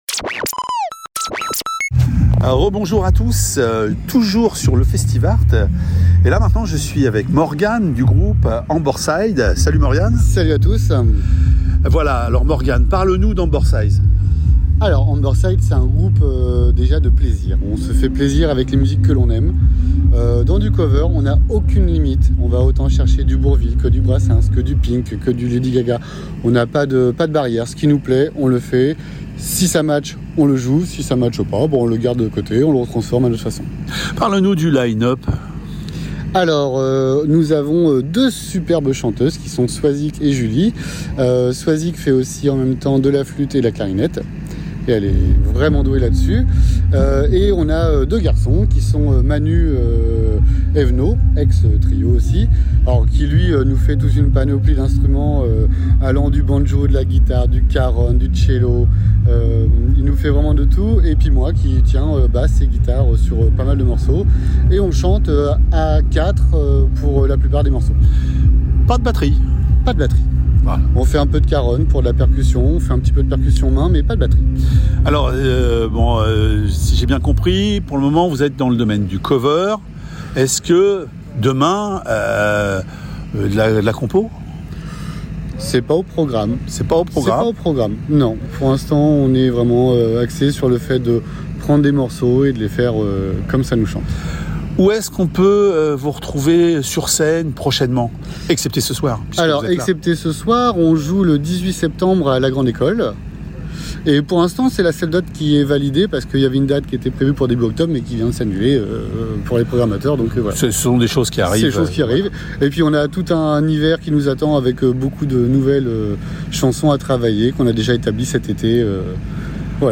Interview du groupe